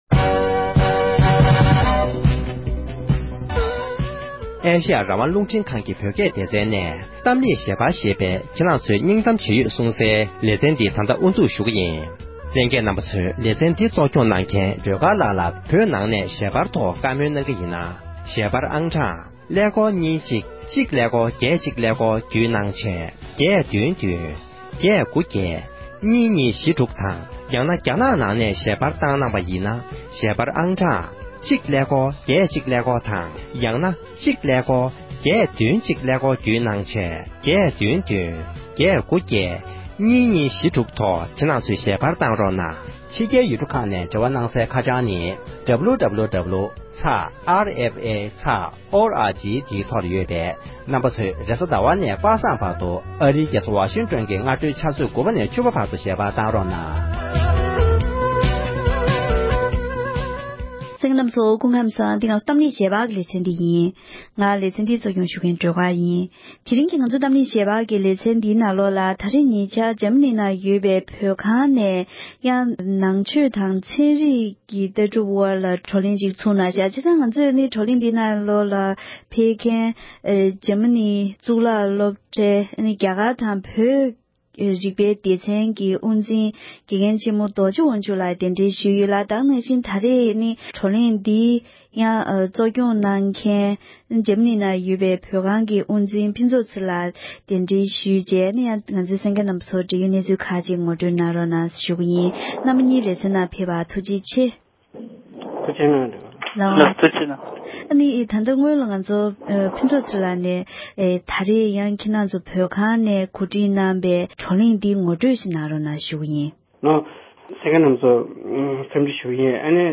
འབྲེལ་ཡོད་མི་སྣ་དང་ལྷན་དུ་བཀའ་མོལ་ཞུས་པ་ཞིག